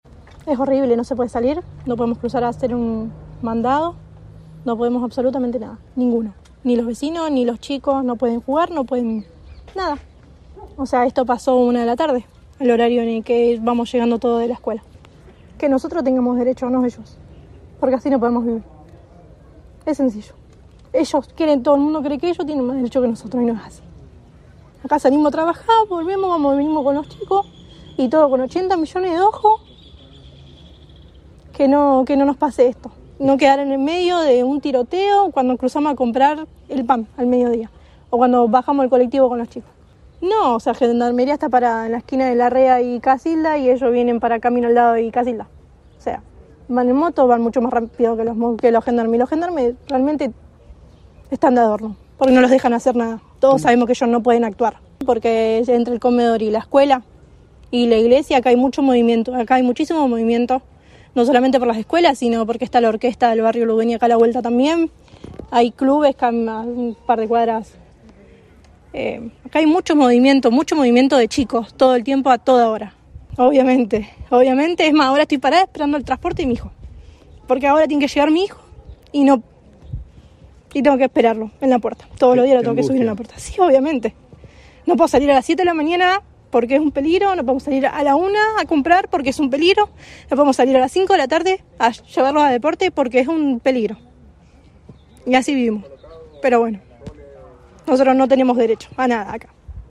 El crudo testimonio.
Vecina-de-Luduena-por-las-balaceras.mp3